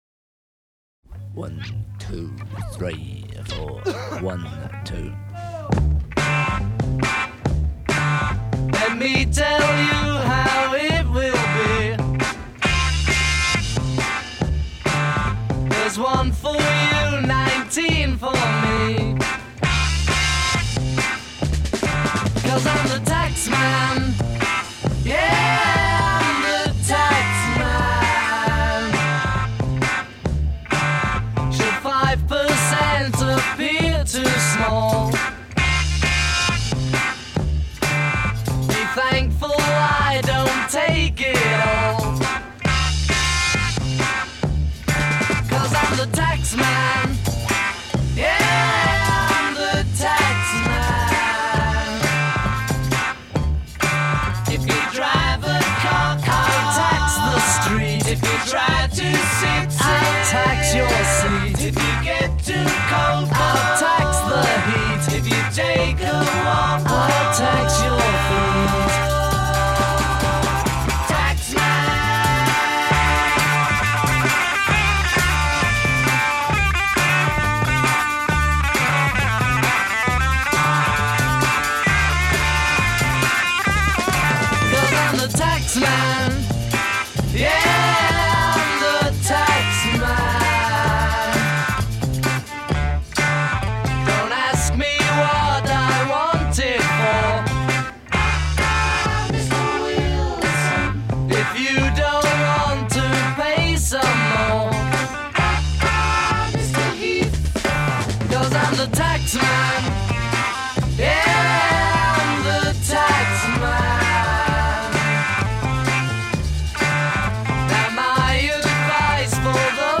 electric guitar-rock sound